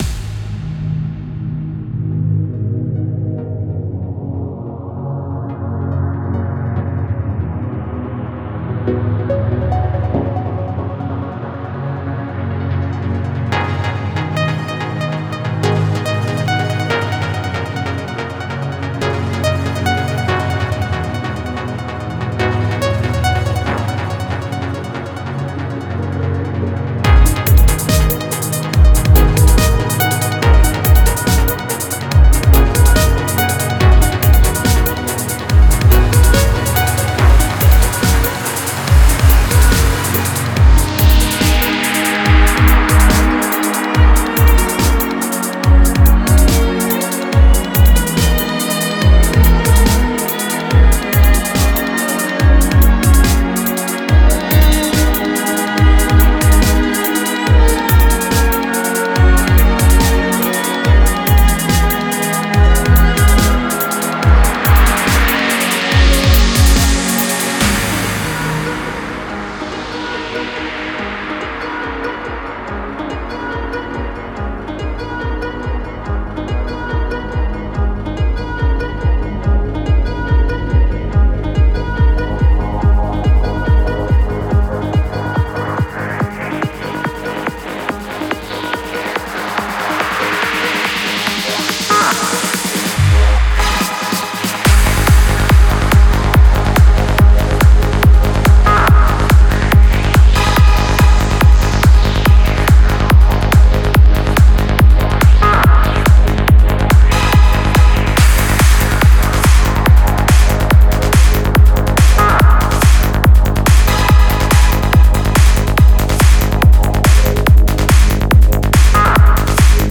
Style: FullOn